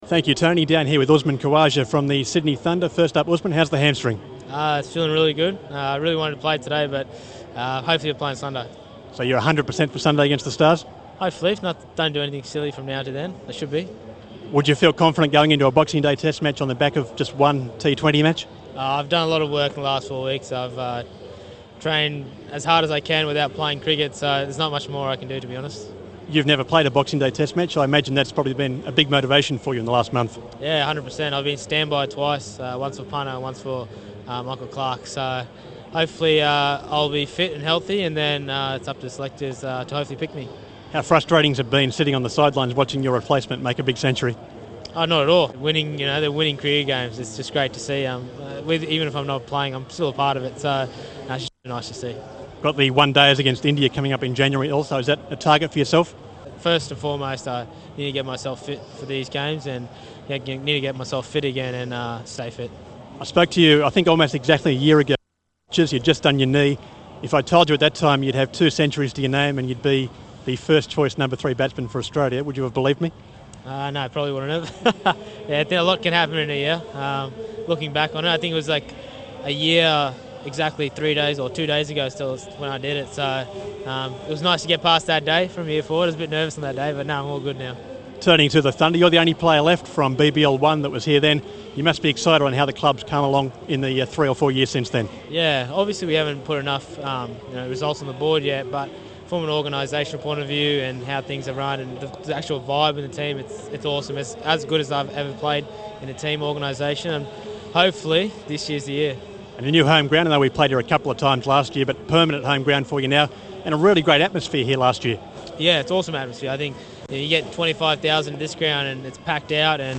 Australian and Sydney Thunder batsman Usman Khawaja spoke about his progress from injury in the lead-up to the Boxing Day Test.